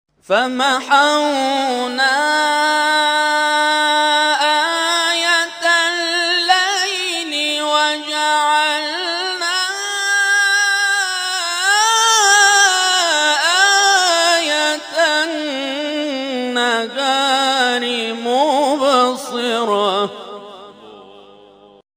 گروه جلسات و محافل ــ محفل انس با قرآن این هفته آستان عبدالعظیم الحسنی(ع) با تلاوت قاریان ممتاز و بین‌المللی کشورمان برگزار شد.